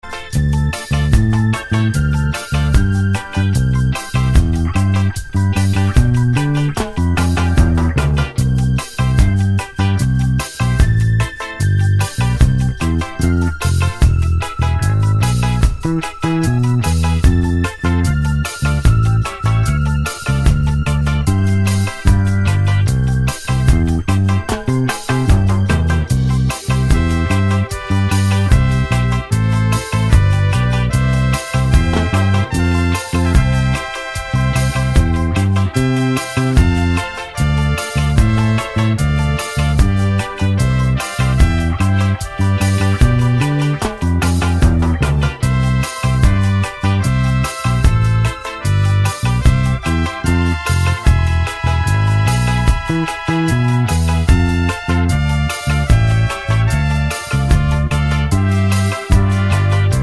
no Backing Vocals Duets 3:48 Buy £1.50